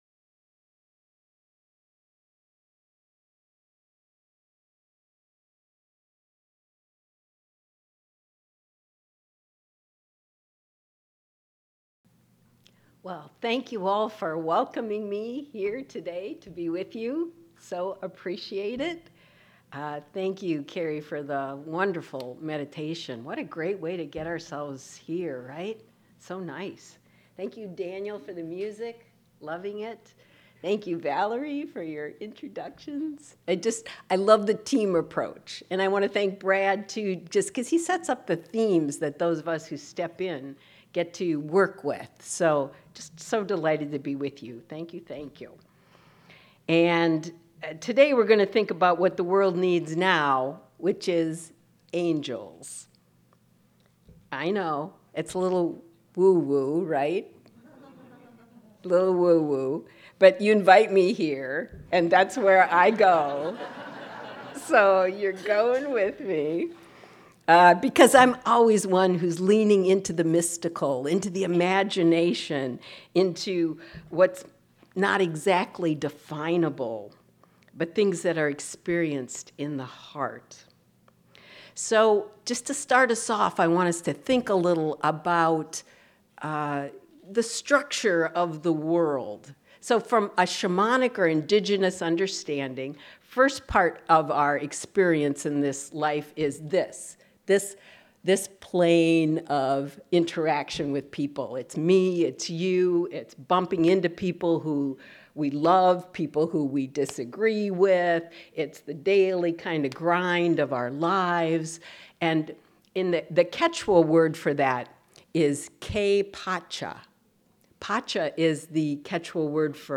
Listen to one of our many guest speakers on the stage at C3 West Michigan's Inclusive Spiritual Community.